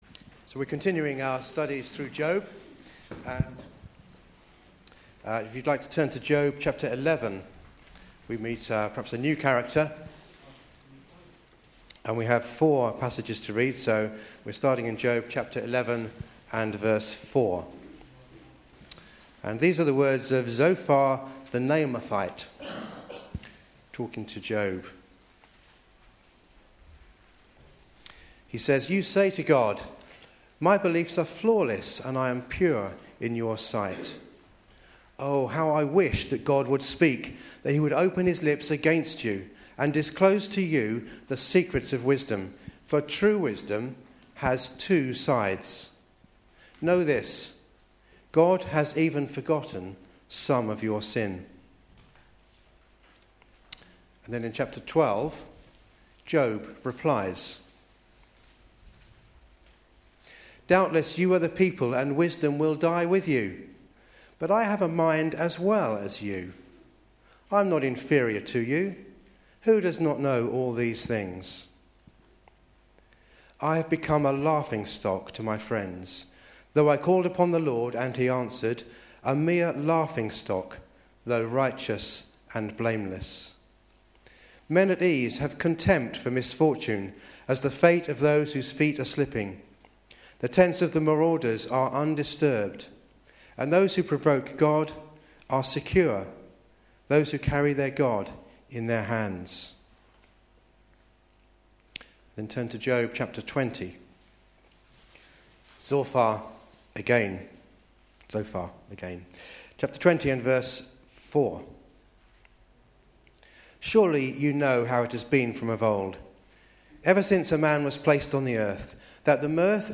Sunday Service
Sermon